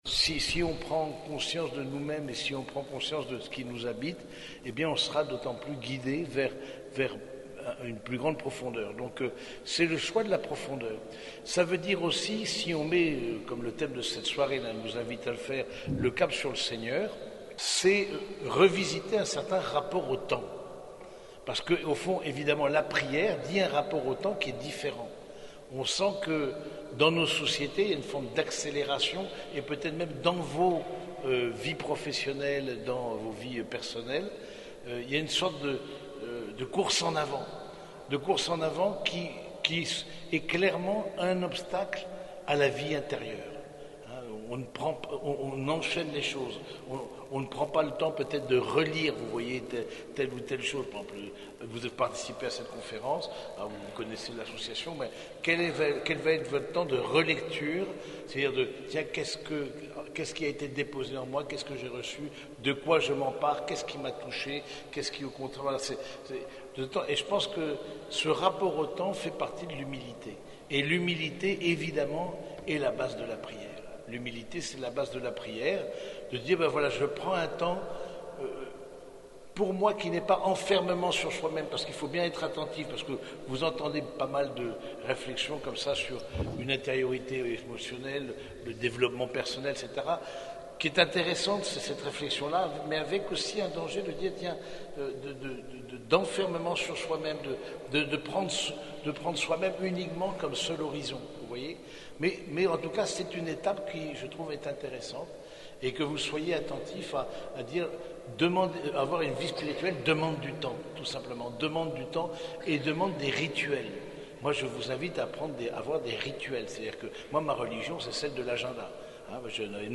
Conférence Spi&Spi d’Octobre 2024